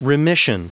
Prononciation du mot remission en anglais (fichier audio)
Prononciation du mot : remission